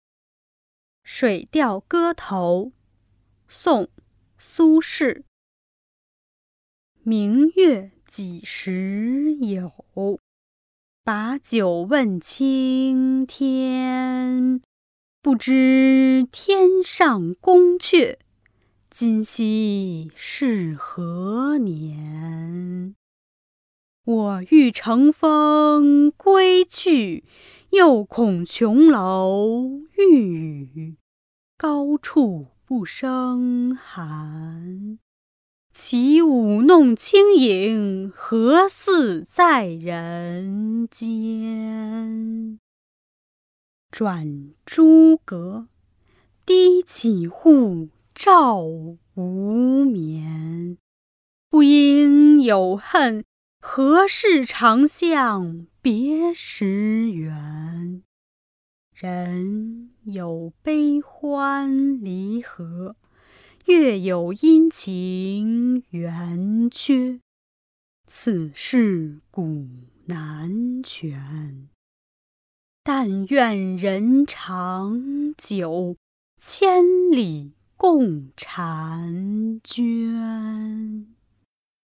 ［宋］苏轼 《水调歌头》（明月几时有）（读诵）